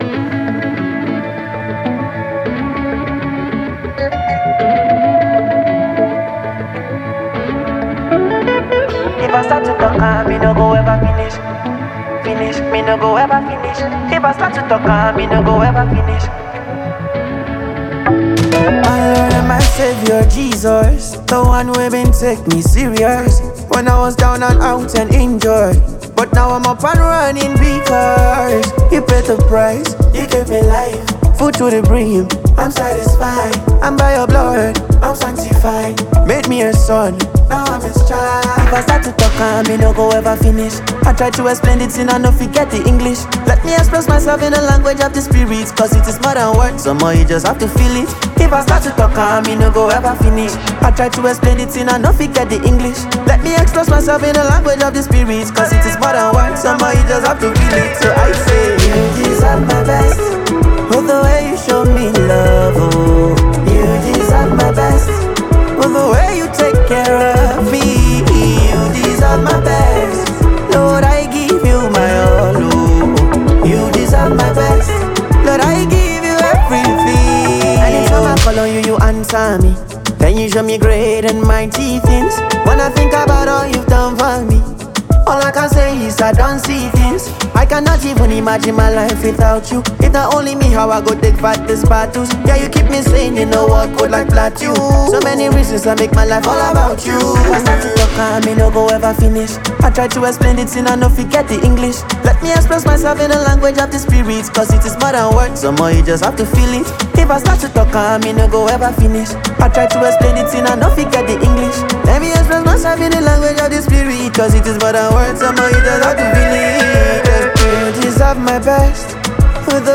Nigerian Afrobeat gospel